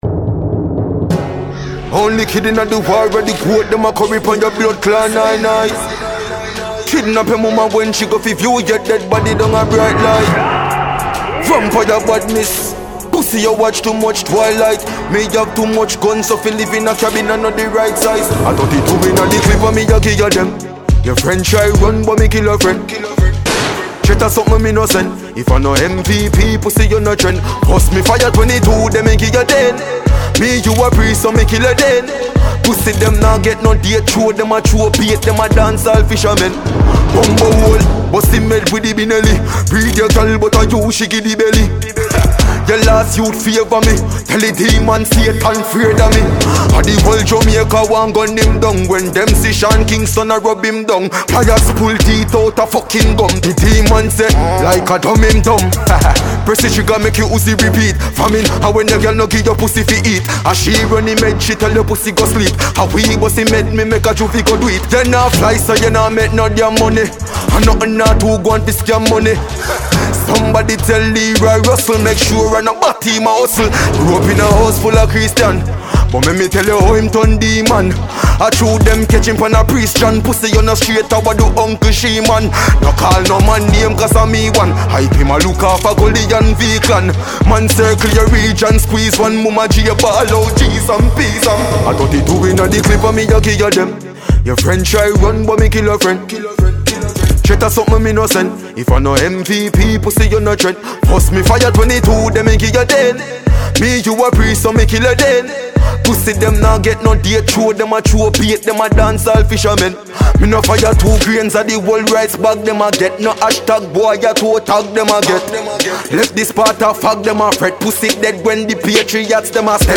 Dancehall Music
a Jamaican dancehall act